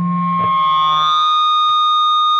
PRS FBACK 2.wav